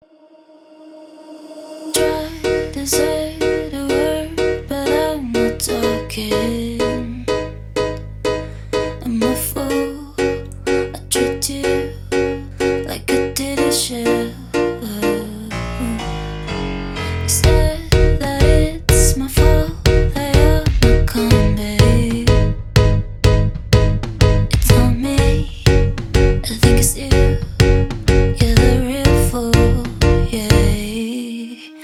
спокойные
Dance Pop
красивый женский голос
Поп музыка